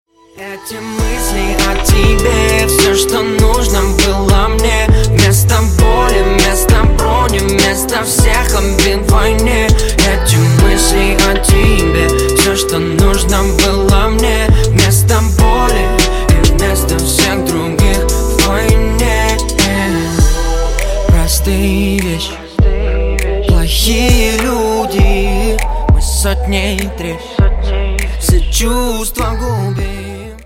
• Качество: 320, Stereo
Хип-хоп
красивый мужской голос
русский рэп
спокойные
романтические